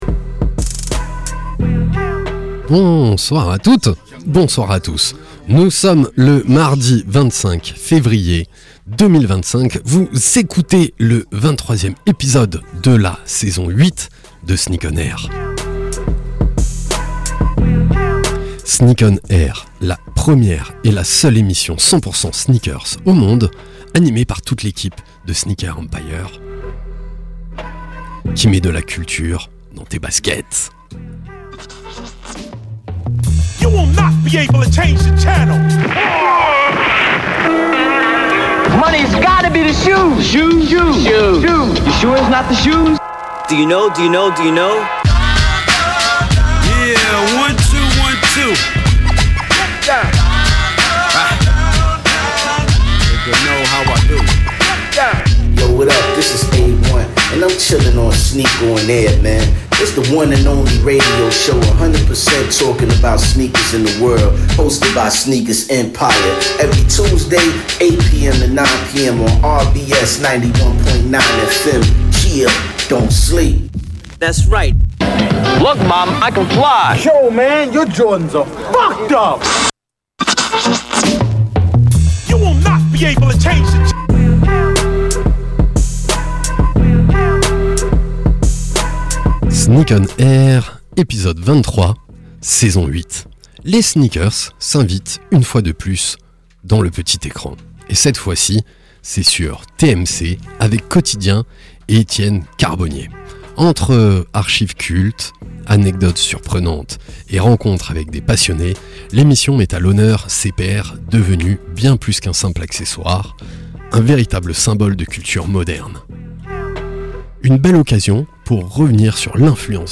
Sneak ON AIR, la première et la seule émission de radio 100% sneakers au monde !!! sur la radio RBS tous les mardis de 20h à 21h. Animée par l’équipe de Sneakers EMPIRE.